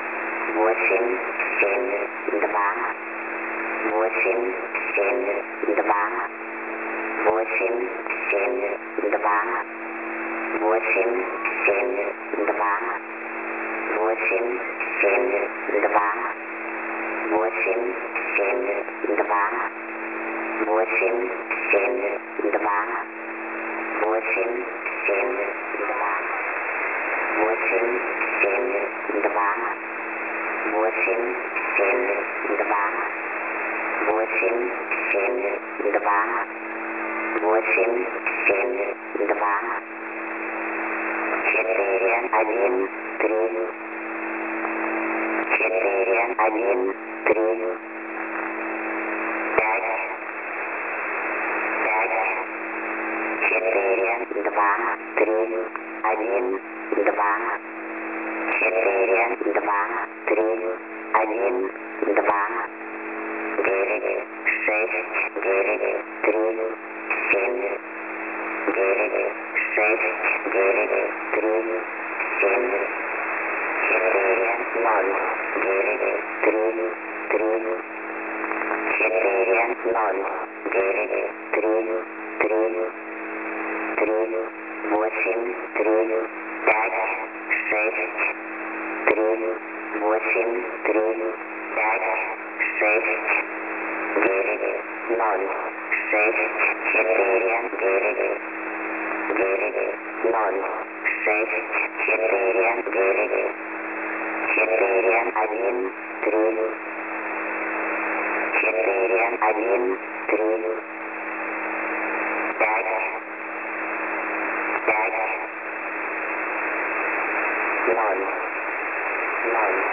S06s W/ Broken Transmitter | The NSRIC Database
Mode: USB + Carrier